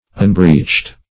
Unbreeched \Unbreeched\